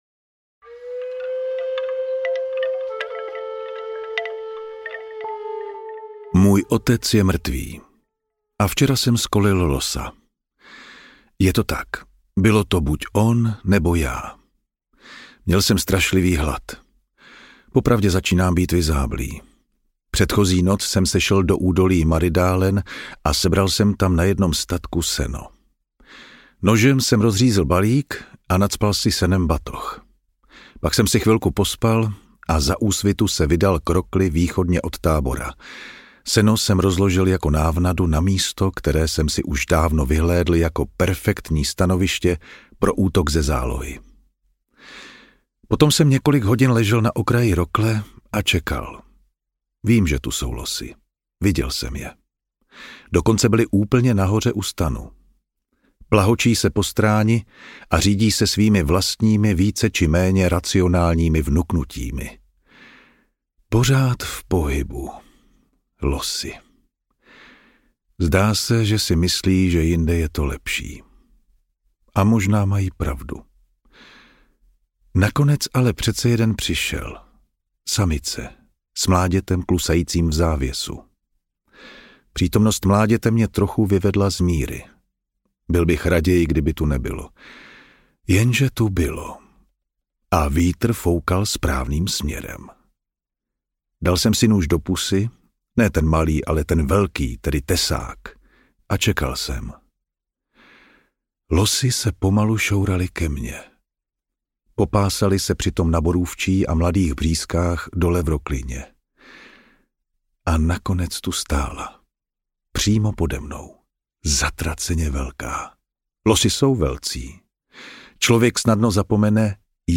Doppler audiokniha
Ukázka z knihy
• InterpretJan Vondráček